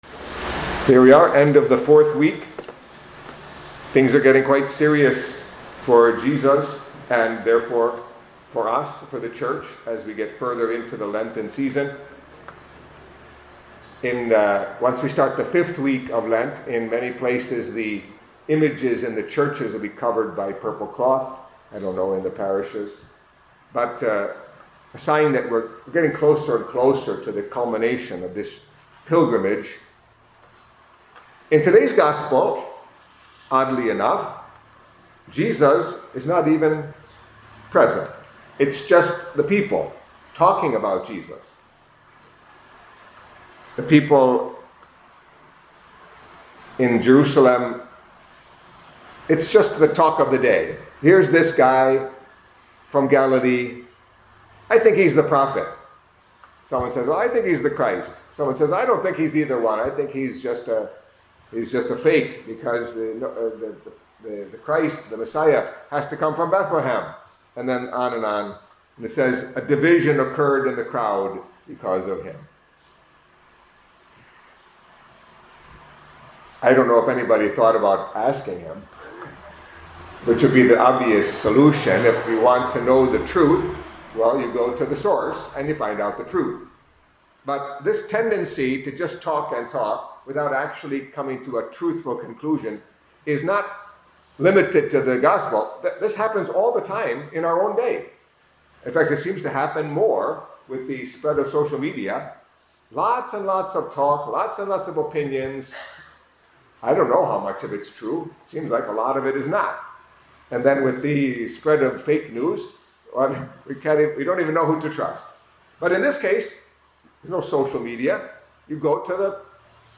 Catholic Mass homily for Saturday of the Fourth Week of Lent